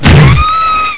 SQUEEKDI.WAV